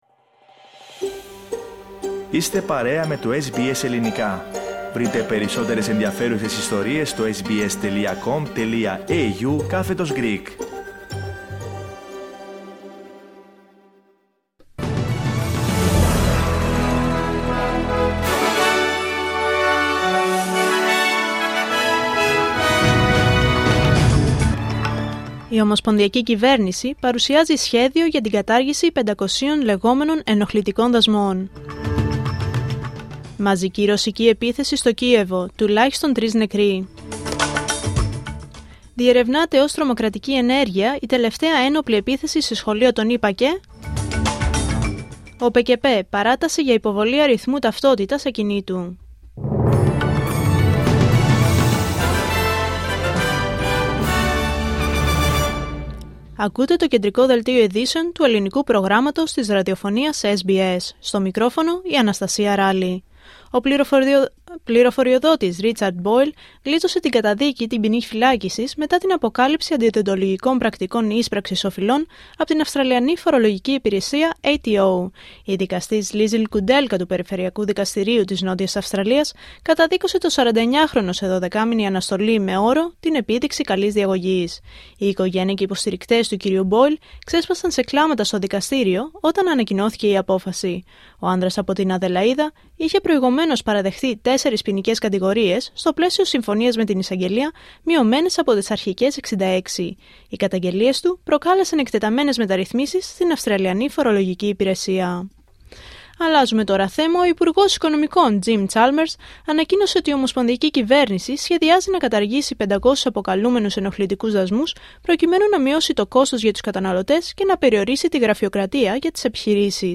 Δελτίο Ειδήσεων Πέμπτη 28 Αυγούστου 2025